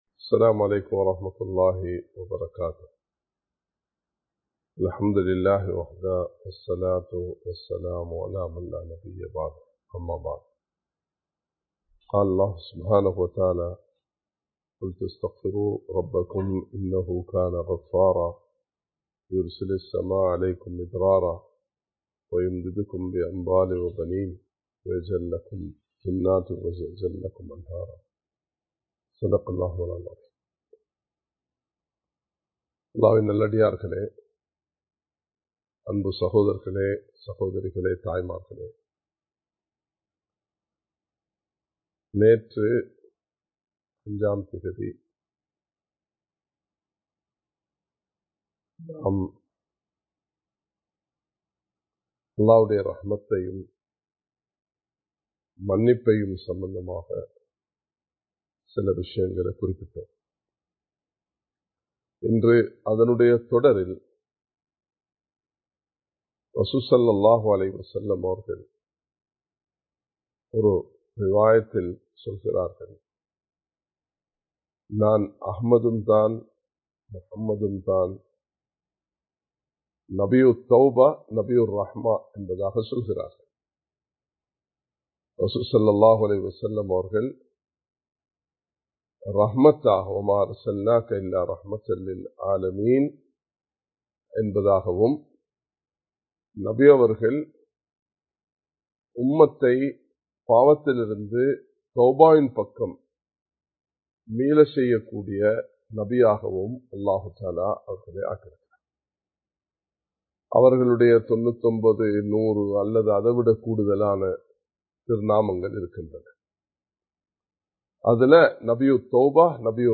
அல்லாஹ்வுடைய இரக்கம் (பகுதி 02) | Audio Bayans | All Ceylon Muslim Youth Community | Addalaichenai
Live Stream